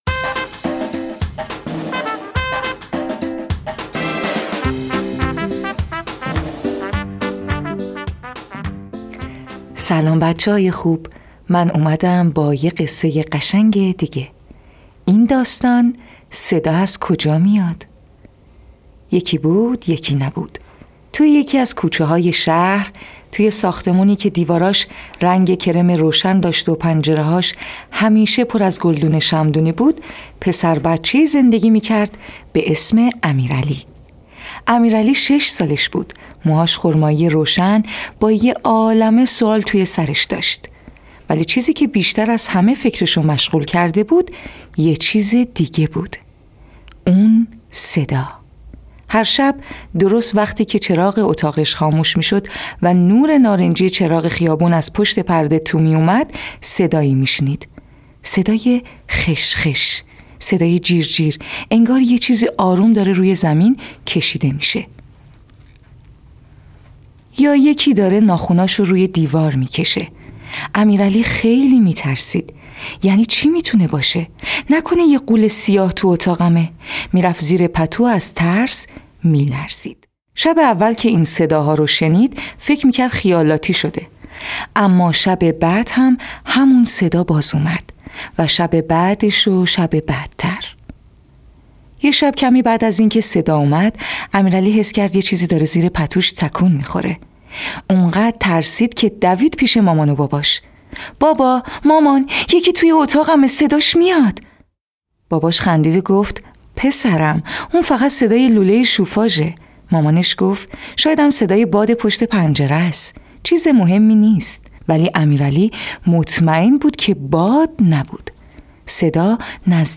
قصه های کودکانه صوتی – این داستان: صدا از کجا میاد؟!
تهیه شده در استودیو نت به نت